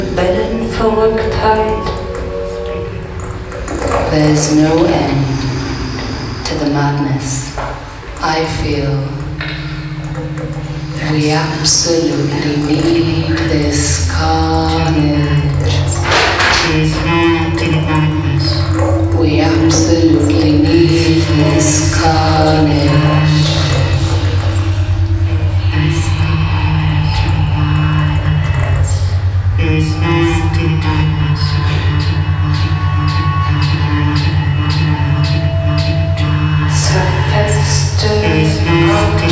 noisy words - workshop - collective language
sonic writing